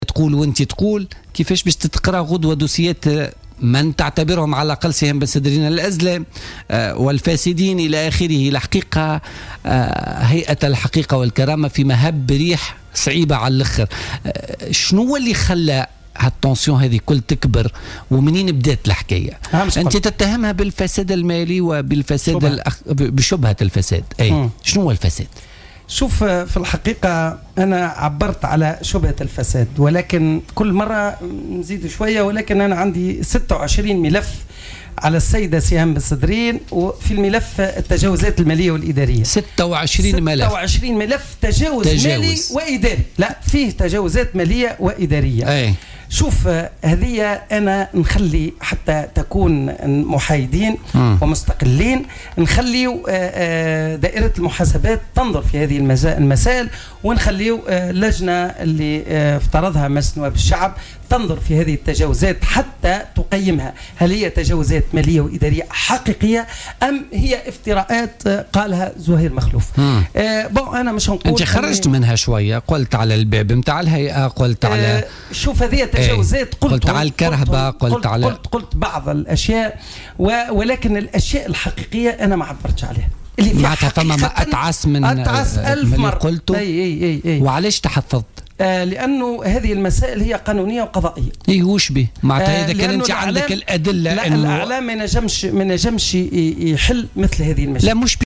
أكد عضو هيئة الحقيقة والكرامة،زهير مخلوف ضيف برنامج "بوليتيكا" اليوم أن بحوزته 26 ملفا حول التجاوزات المالية والإدارية "المفزعة" التي ارتكبتها رئيسة الهيئة سهام بن سدرين.